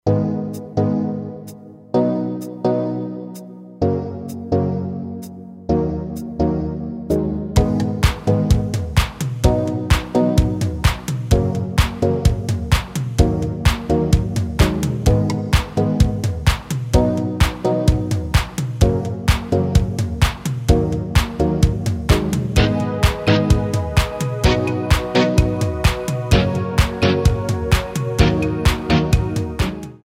klick & play MP3/Audio demo